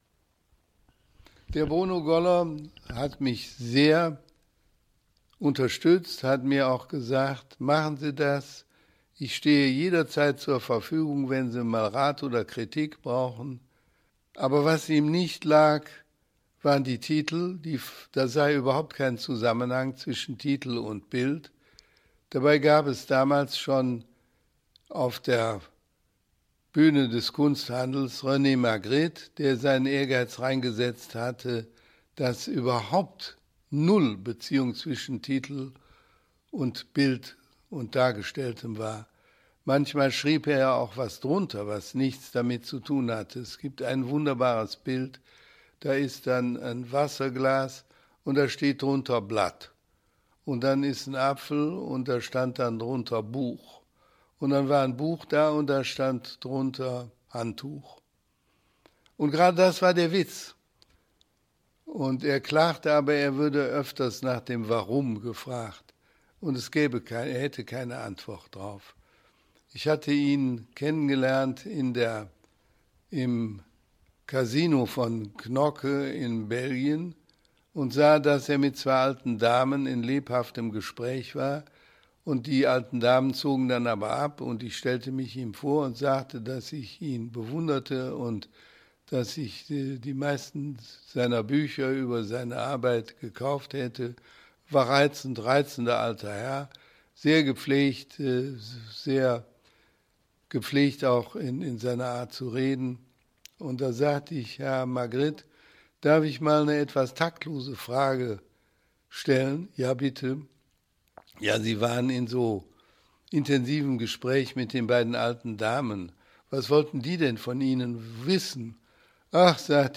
Interview Audioarchiv Kunst: Konrad Klapheck